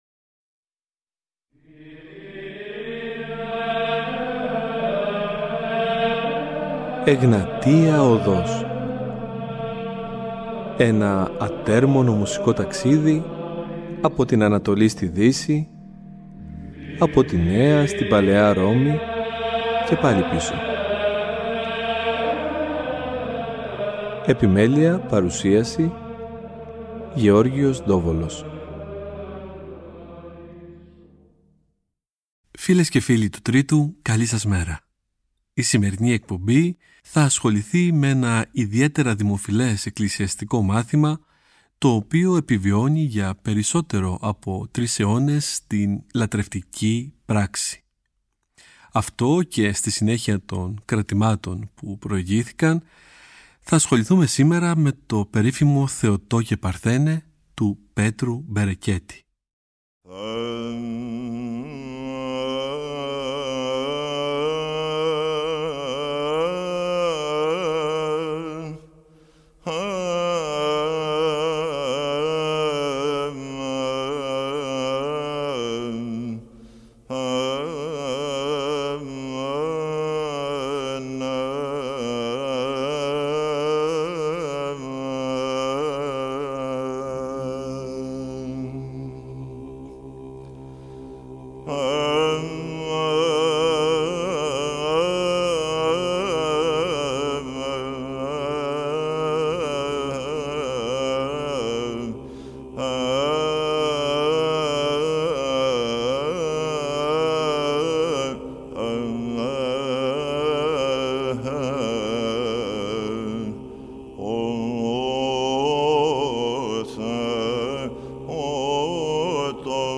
Ακούστε την 1η Εκπομπή, του Σαββάτου 27 Ιουλίου, όπως μεταδόθηκε από το Τρίτο Πρόγραμμα.
Ψάλλεται κατά την Αρτοκλασία στους πανηγυρικούς Εσπερινούς, εναλλάξ κι από τους δύο χορούς, και στις μεγάλες Αγρυπνίες (του Αγ. Όρους).
Βυζαντινη Μουσικη
Εκκλησιαστικη Μουσικη